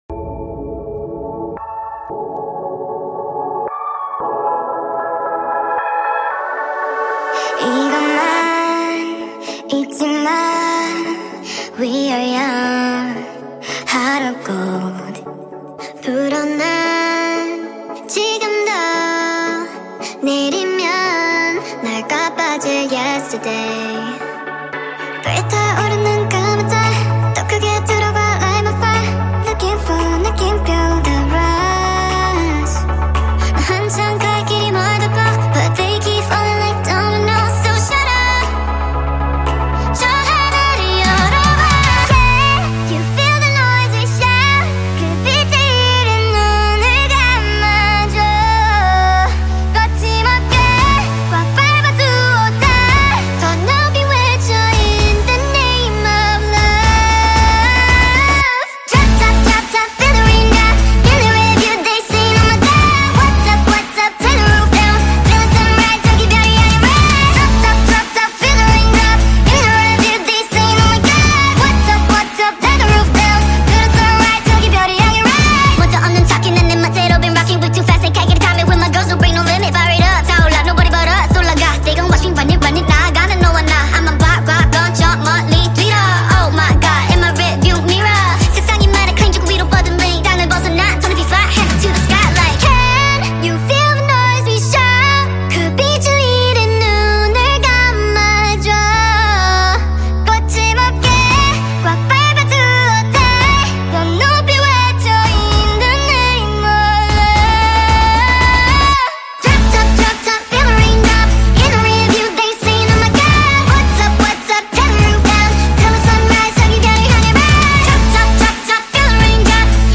SPEED UP FULL SONG